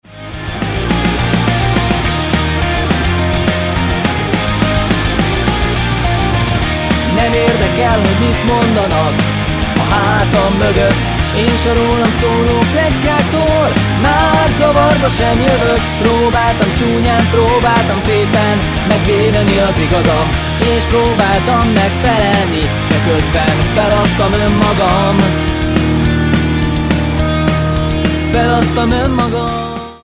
Rockos hangvétel